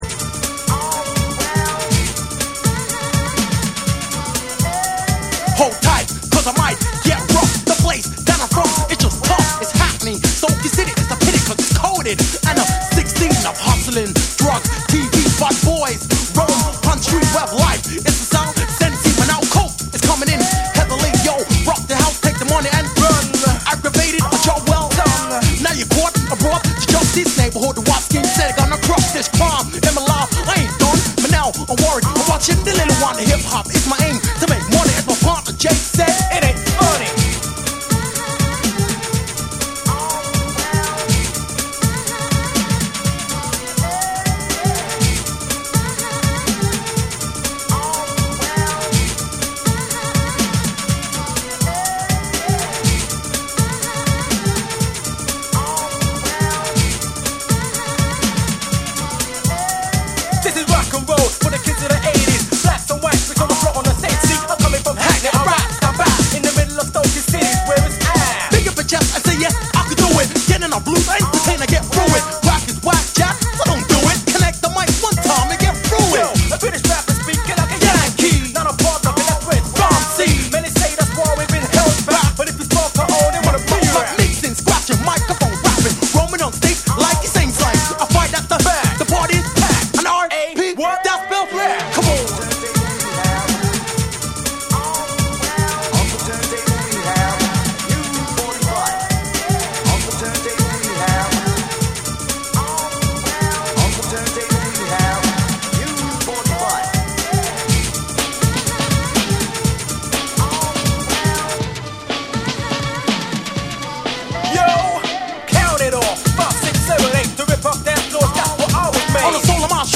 レイブにも通ずる要素を含むヒップハウスのトラックに、煽りを効かせた男性MCがフロウする
BREAKBEATS / TECHNO & HOUSE